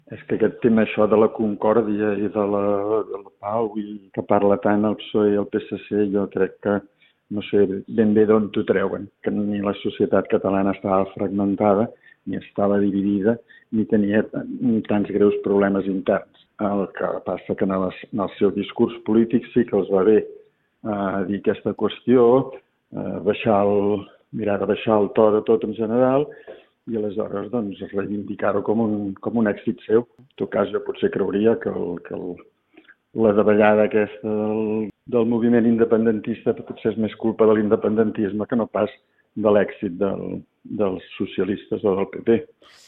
en una entrevista a RCT.